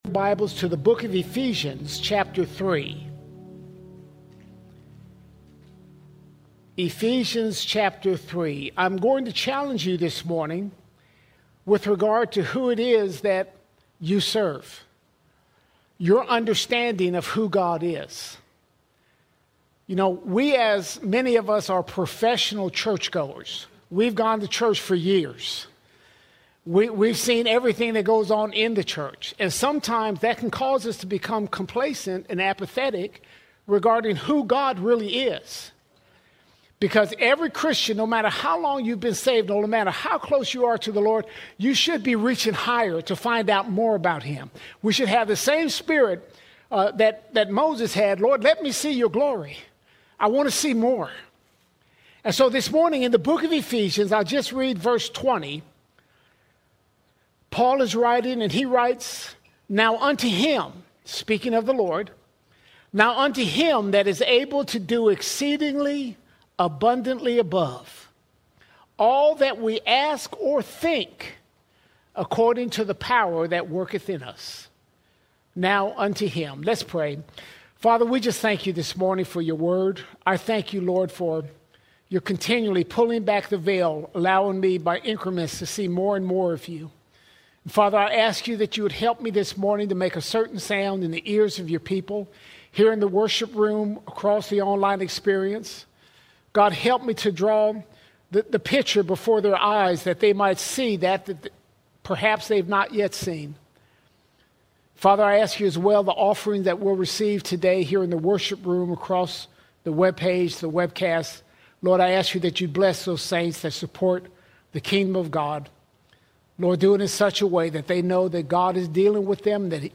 27 October 2025 Series: Sunday Sermons All Sermons God of No Limits God of No Limits We serve a God without limits!